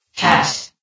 CitadelStationBot df15bbe0f0 [MIRROR] New & Fixed AI VOX Sound Files ( #6003 ) ...